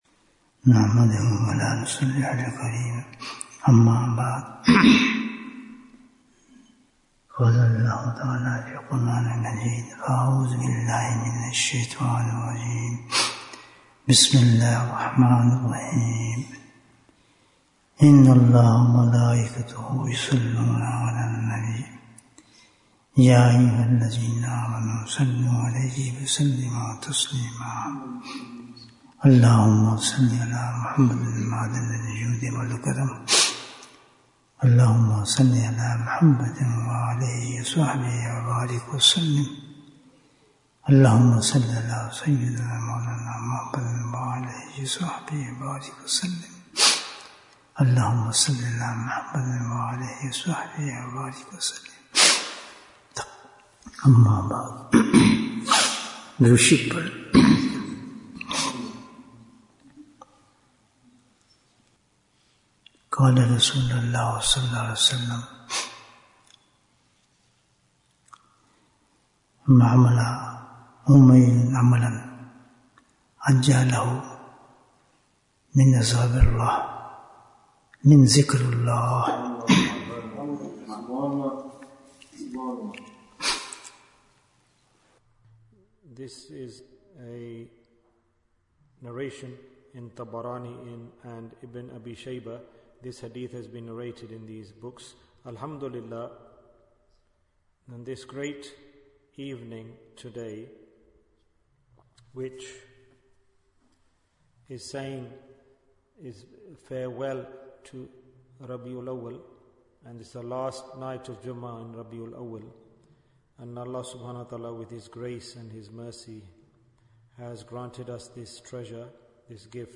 Are We in Punishment or Rahmah? Bayan, 67 minutes3rd October, 2024